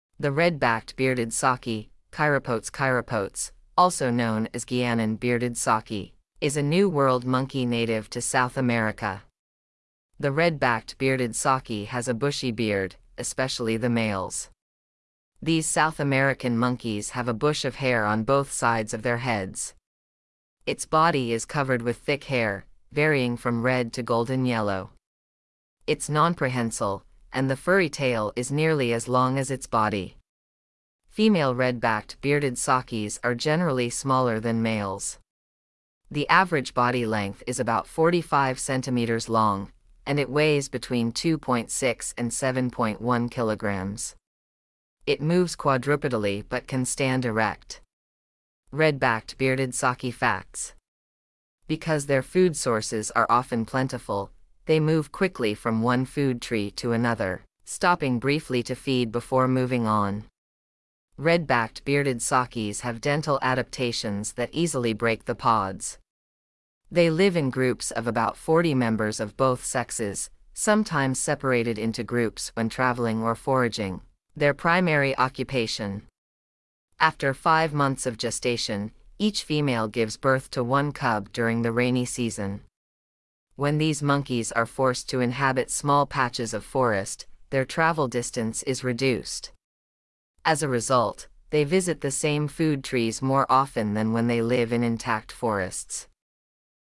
Red-backed Bearded Saki
red-backed-bearded-saki.mp3